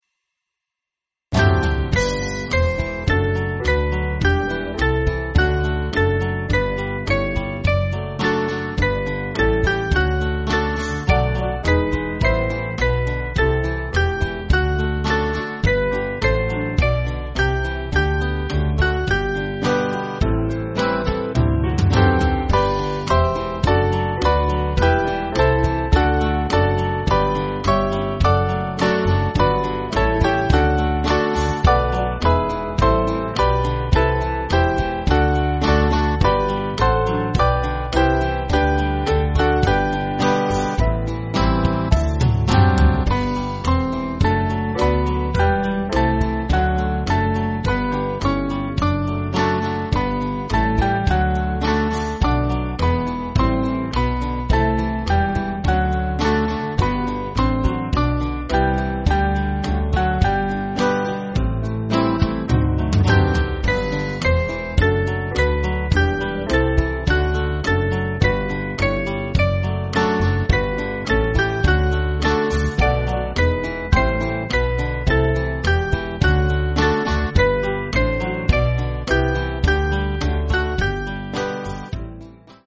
8.8.8.8 with Refrain
Small Band